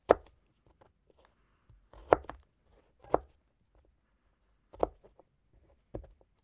Звук очистки чеснока от шелухи и его нарезка